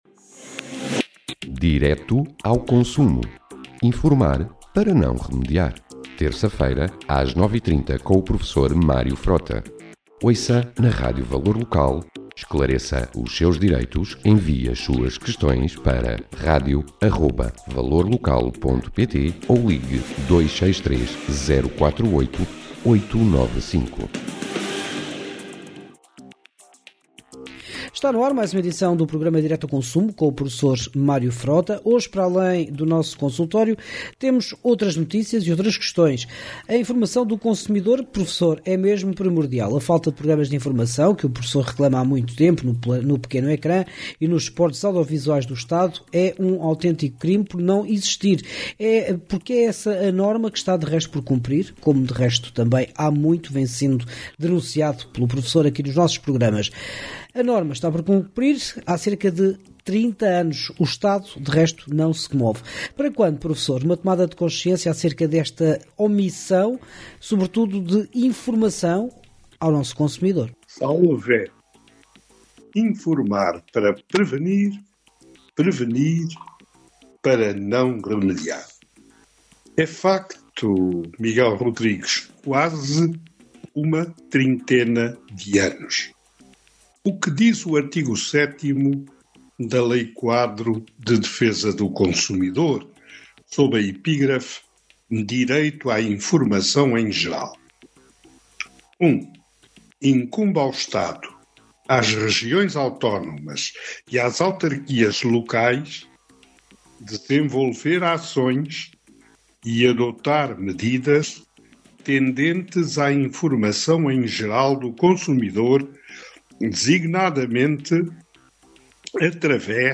No programa de hoje o professor responde ás questões dos nossos ouvintes. Desde assuntos relacionados com saúde, passando pela compra de um simples caderno.